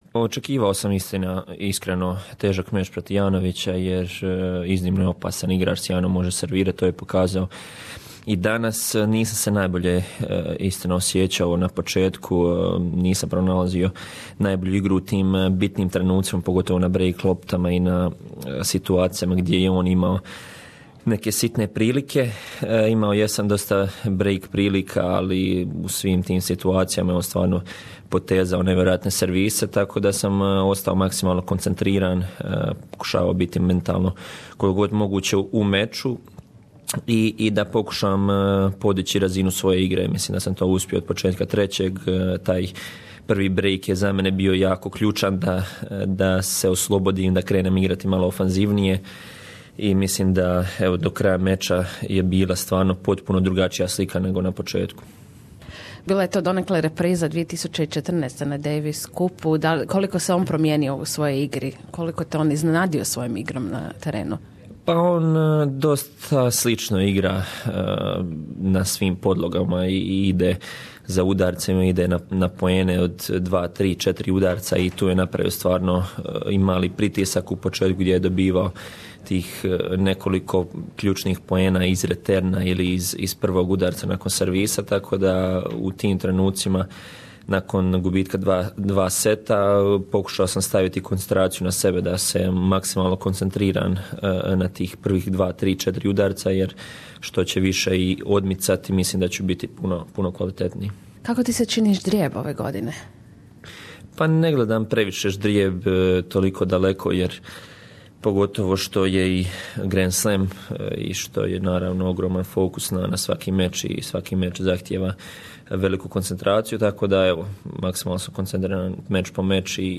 On je pobijedio Jerzyija Janowiczea iz Poljske rezultatom: 4-6, 4-6, 6-2, 6-2, 6-3 u meču koji je trajao nešto više od 3 sata. Nako susreta upitali smo Čilića da se za Radio SBS osvrne na meč, te što ga čeka u 2. kolu i susretu s Danielom Evansom iz Velike Britanije. Marin Čilić također govori o pripremama s novim trenerima, te o svojoj odluci o Davis Cup reprezentaciji koja će igrati protiv Španjolske u veljači.